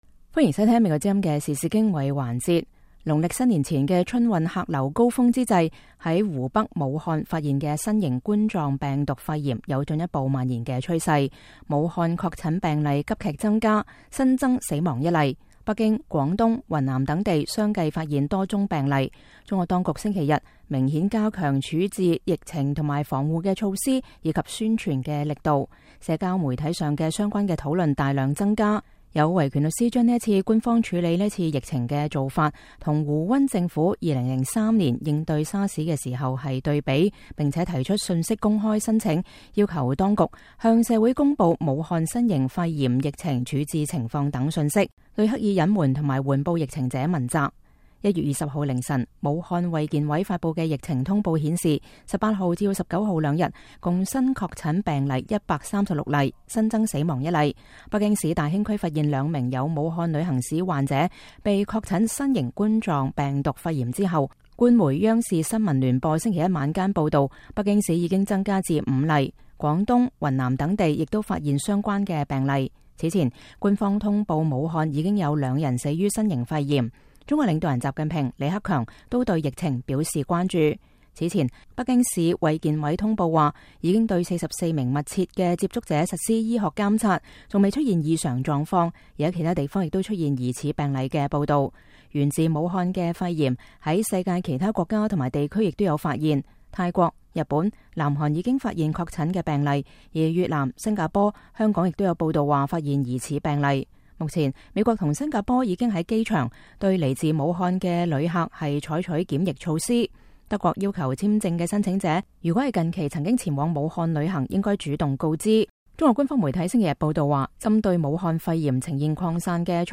記者星期天下午到春運期間格外繁忙的北京西站隨機採訪了一些來自武漢和其他地區的旅客。